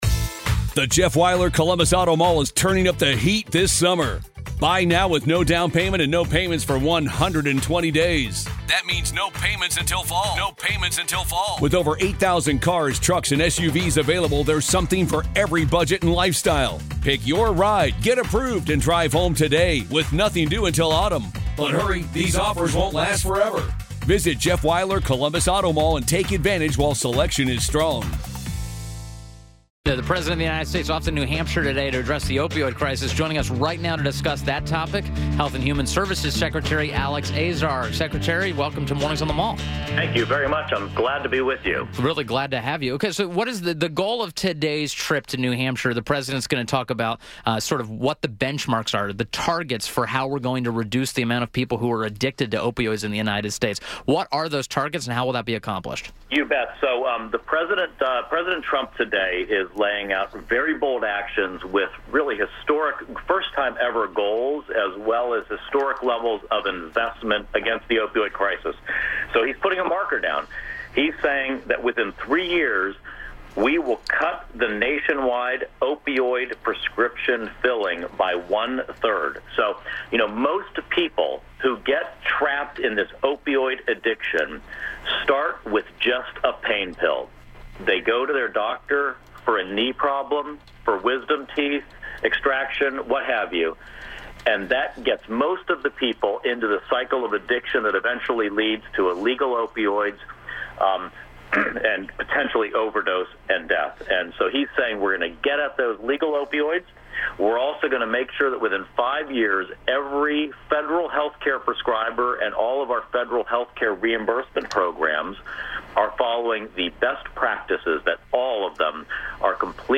WMAL Interview - HHS Secretary ALEX AZAR - 03.19.18